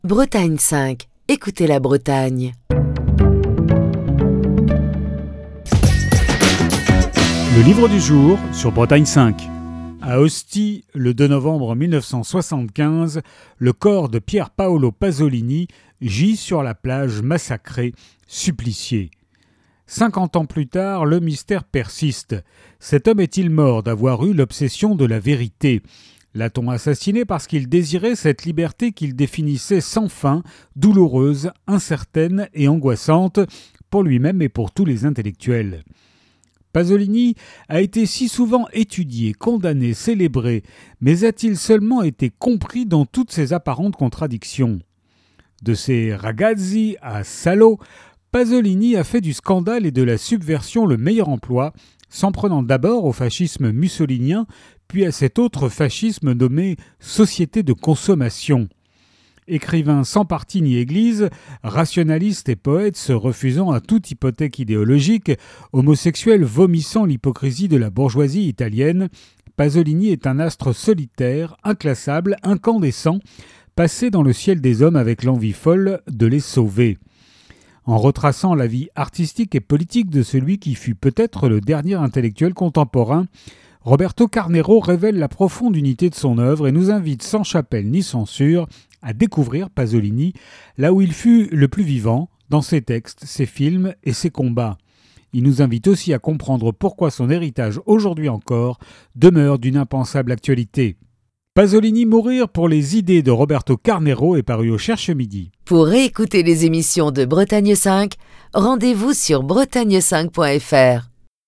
Chronique du 17 décembre 2025.